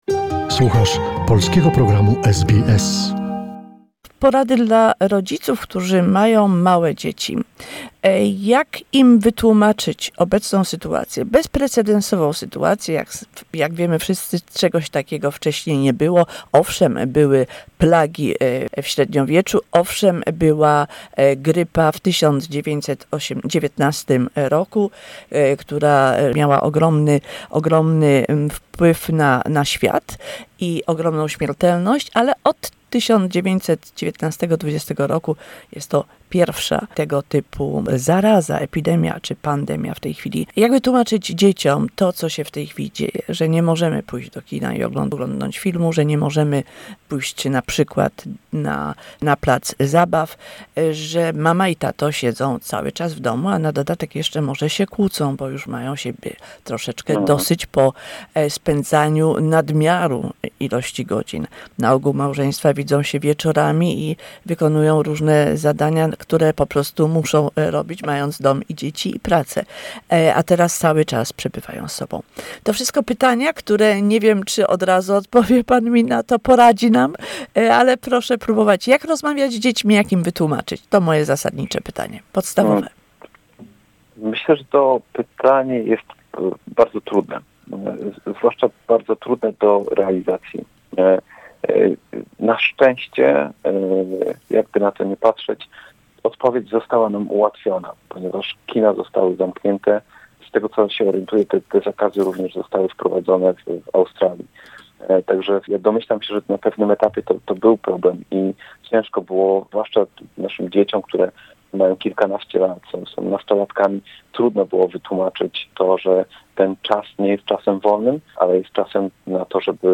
How do you explain a pandemic to your child? A conversation